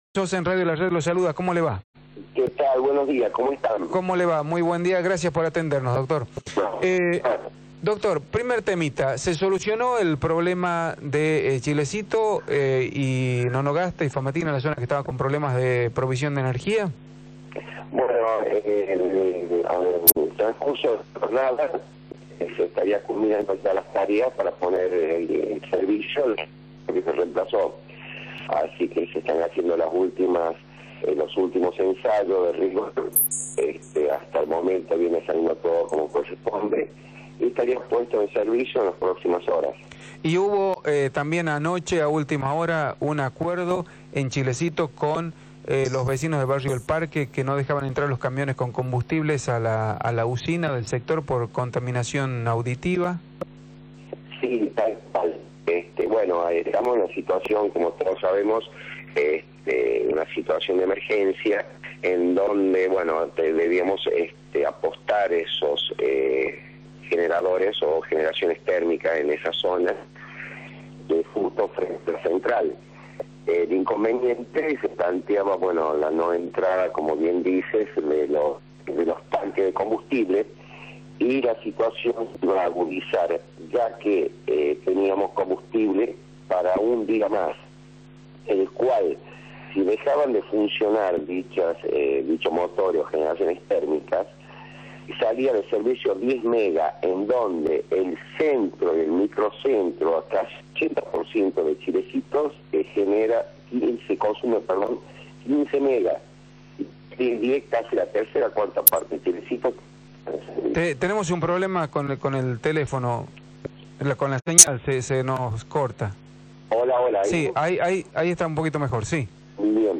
Remo Bolognesi, titular del EUCOP, ente encargado de controlar las empresas privadas prestadoras de servicios en La Rioja, dijo a Radio La Red, que existe una “situación límite” en la infraestructura del servicio de energía eléctrica en la provincia.
remo-bolognesi-titular-del-eucop-por-radio-la-red.mp3